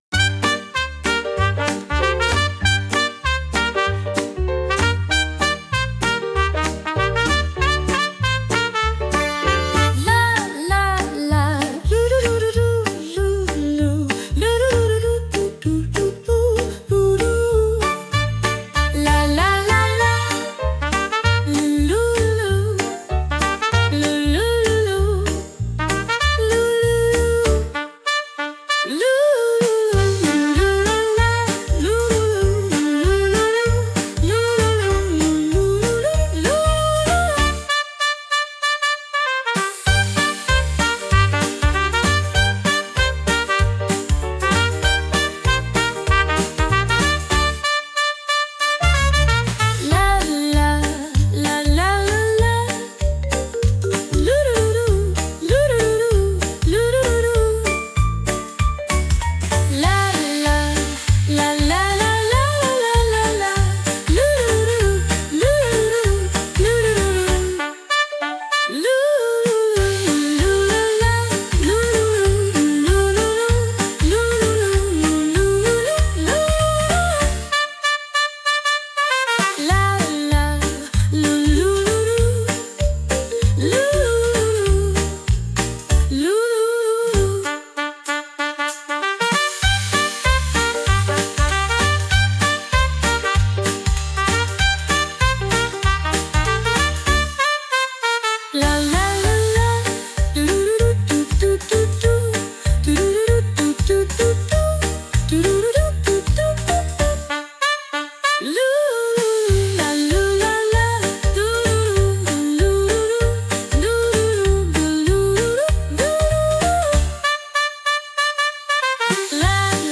ジュニア世代の選手にぴったりな、とびきり可愛くて優しいポップス曲！
この曲の最大の魅力は、誰もが安心する「ゆったりとした踊りやすいテンポ」です。